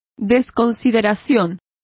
Complete with pronunciation of the translation of thoughtlessness.